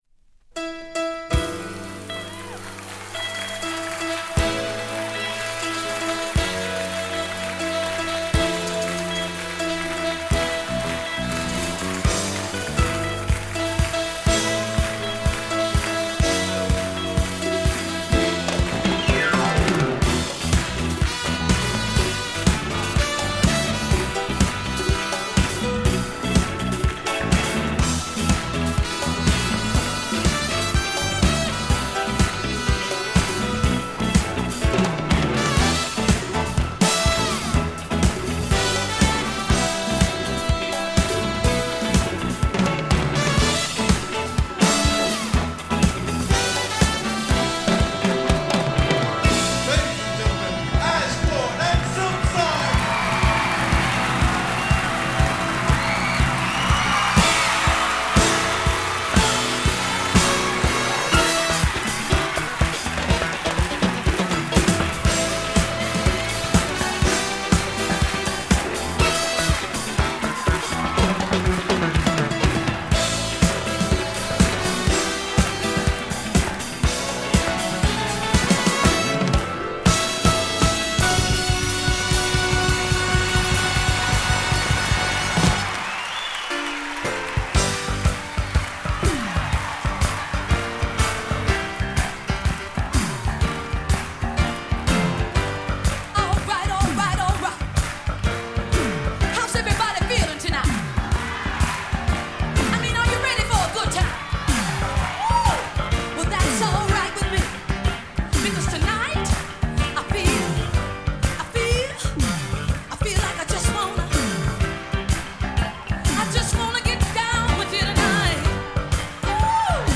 盤面には酷い傷はありませんが、微細な薄いスレはあります。
多少のチリチリ音はあるかと思います。